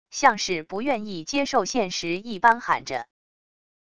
像是不愿意接受现实一般喊着wav音频